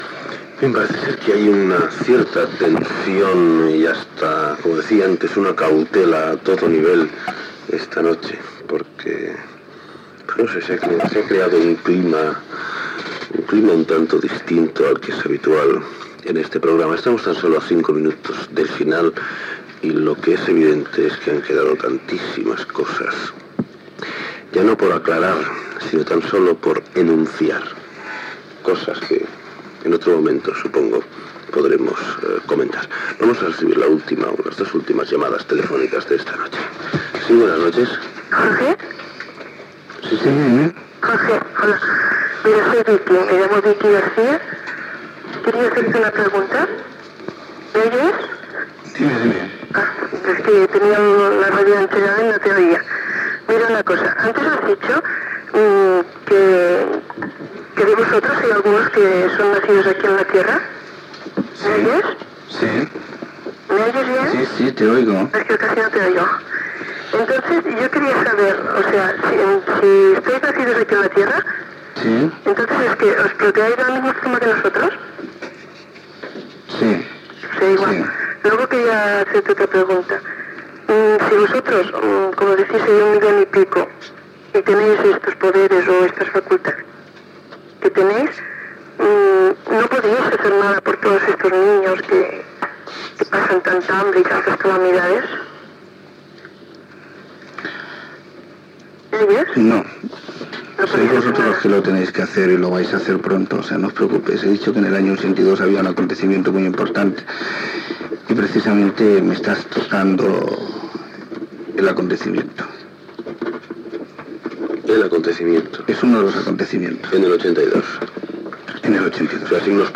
Comiat del programa. Tancament de l'emissió.
Entreteniment
FM